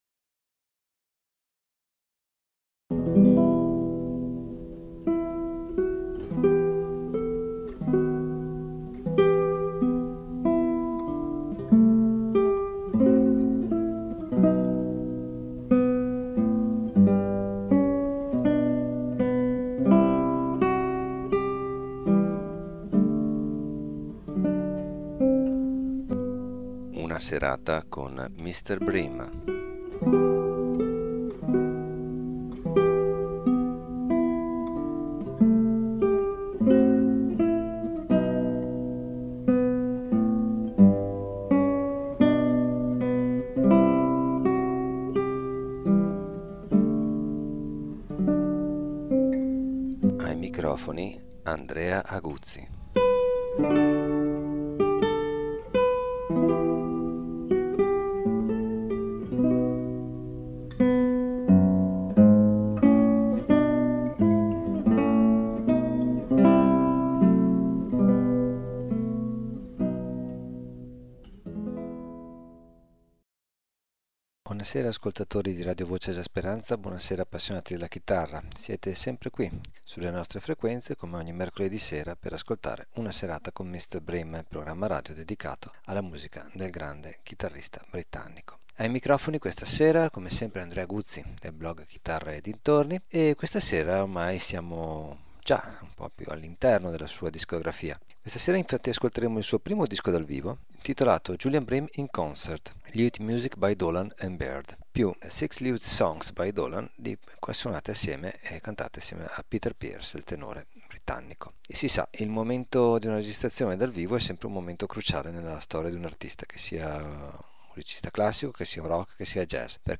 Prima registrazione live